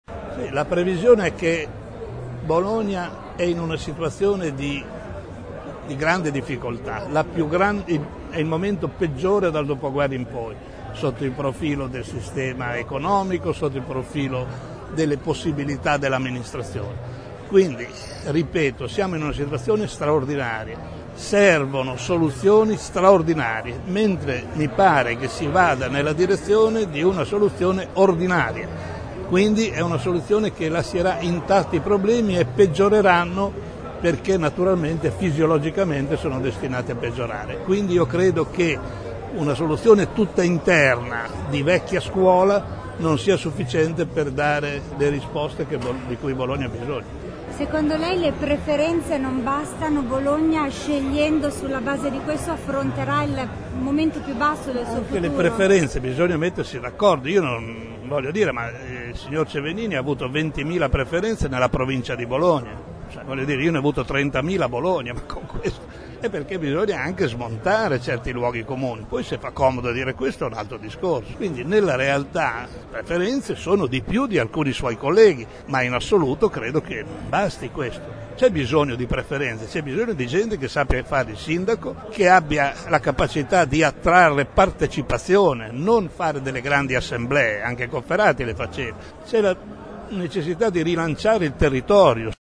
E’ questo il pensiero di Giorgio Guazzaloca, l’ex sindaco che nel 1999 strappò il governo di Bologna dalle mani della sinistra, intervenuto lunedì sera al dibattito in programma alla Festa dell’Unità dal titolo “Idee, proposte, progetti per Bologna”.